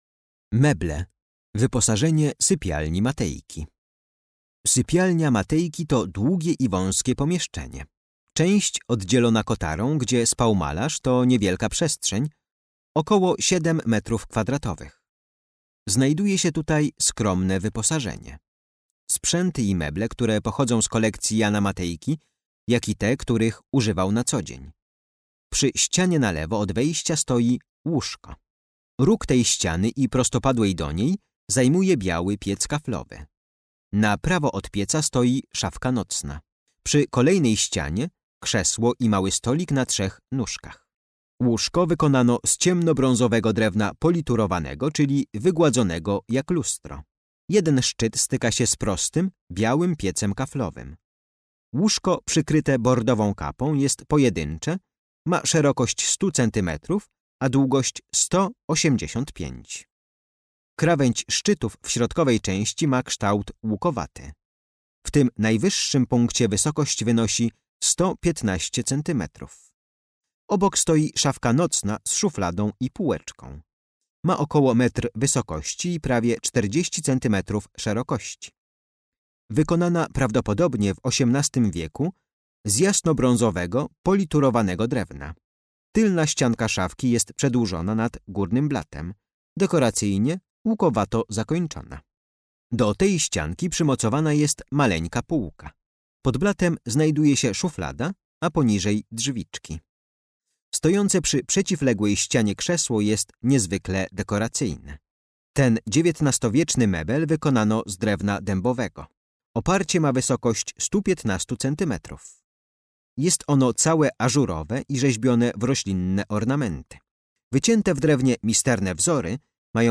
Audiodeskrypcja dla wybranych eksponatów z kolekcji MNK znajdujących się w Domu Jana Matejki.